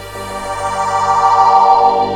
STACKPAD  -L.wav